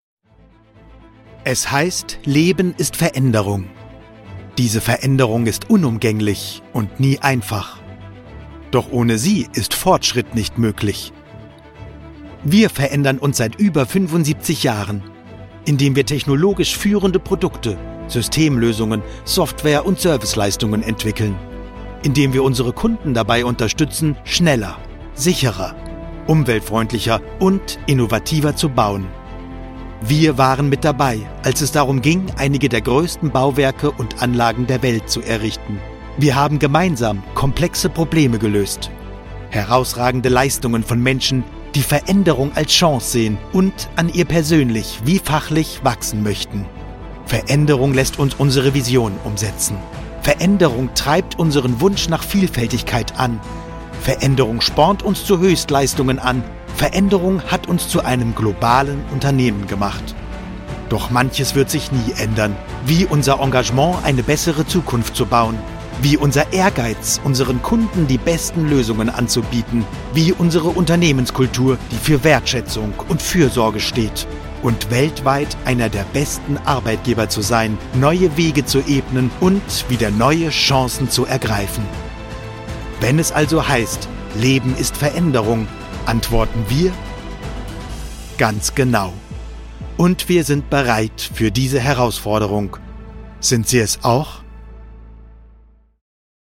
High professional German voice talent: Charming, Emotional, Happy, Hip, Optimistic, Bold, Calm, Charismatic, Cool, Emotional, Motivational
Sprechprobe: Industrie (Muttersprache):